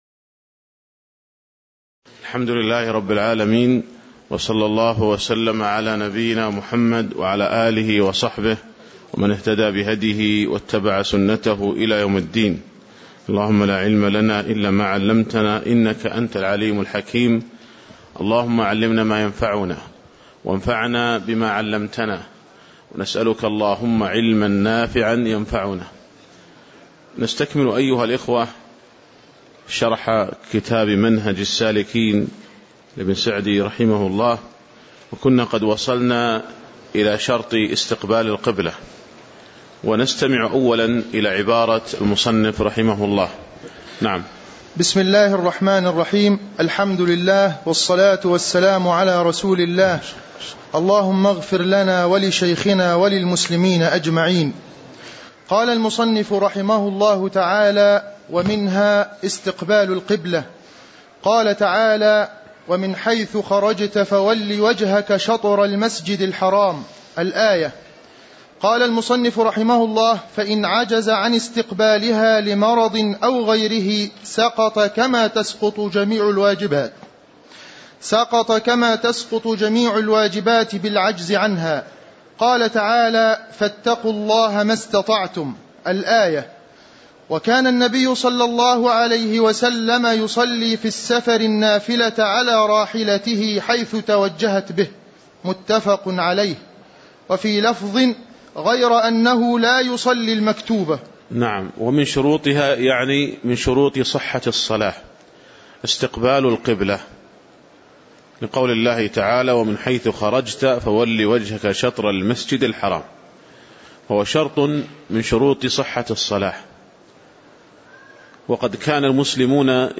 تاريخ النشر ١٦ صفر ١٤٣٨ هـ المكان: المسجد النبوي الشيخ